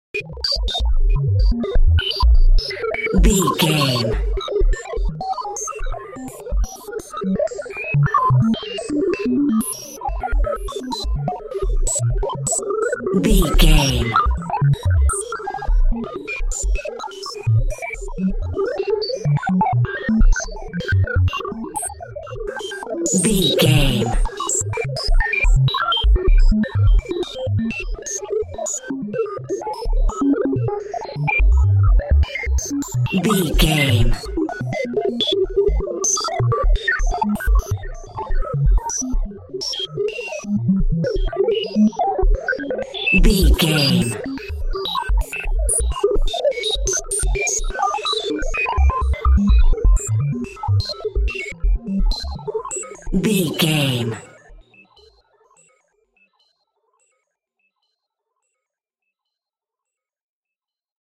In-crescendo
Thriller
Aeolian/Minor
ominous
eerie
Horror synth
Horror Ambience
electronics
synthesizer